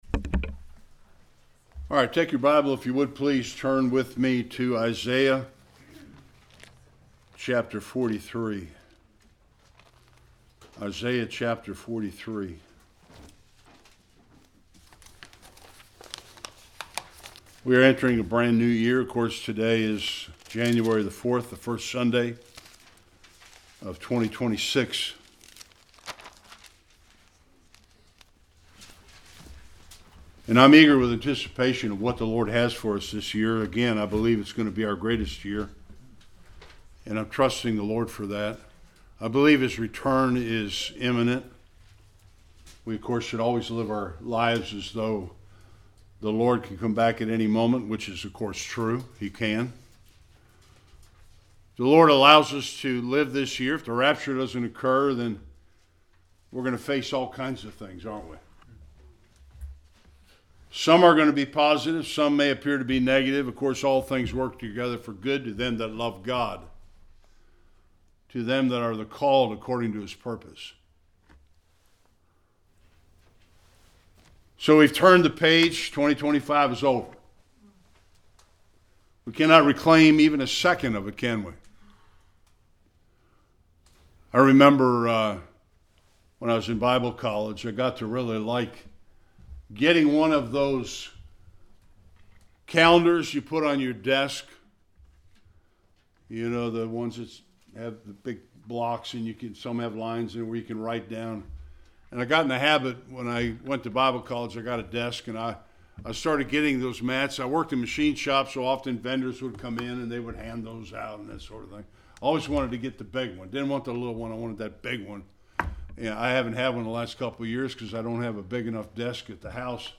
Various Passages Service Type: Sunday Worship A new year brings the possibility for new opportunities for the child of God.